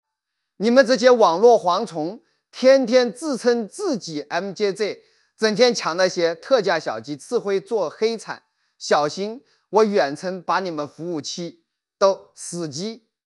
MJJ速度进来听！论坛MJJ惨遭雷军语音批判！